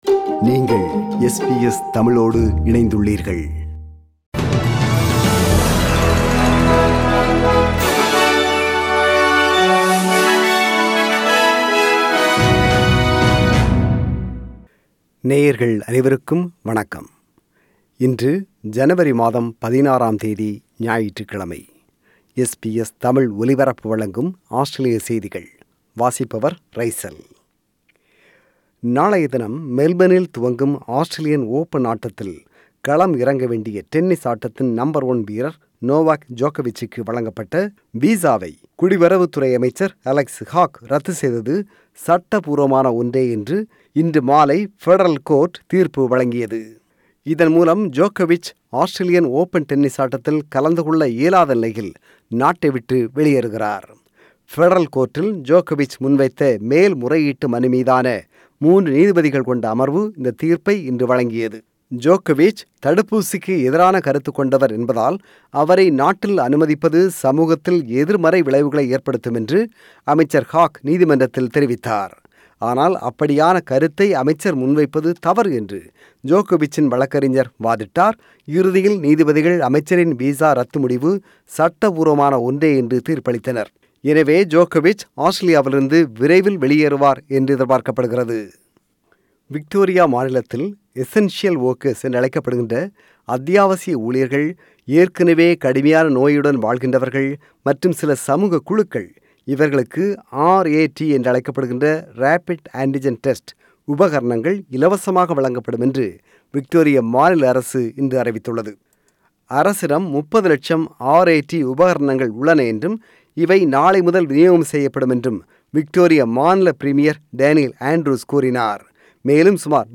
Australian News: 16 January 2022 – Sunday